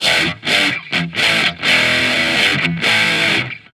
Guitar Licks 130BPM (10).wav